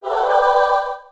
Clear Tombstone Clash Of Clans